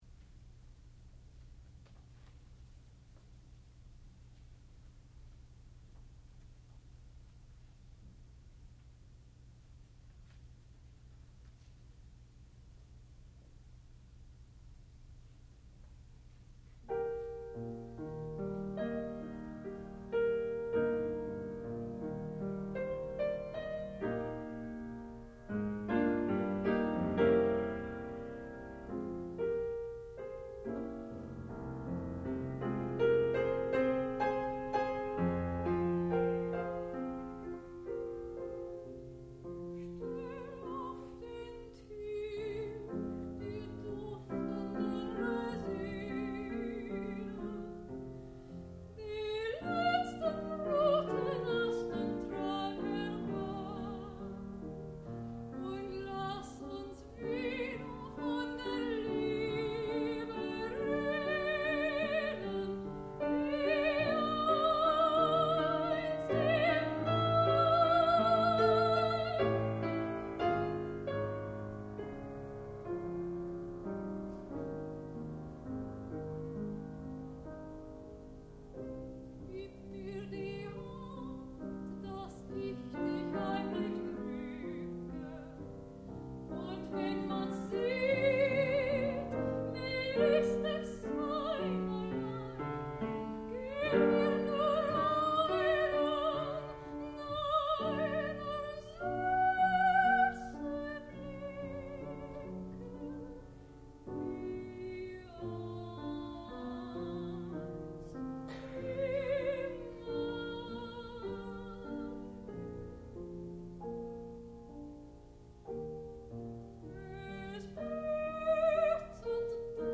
sound recording-musical
classical music
soprano
piano and harpsichord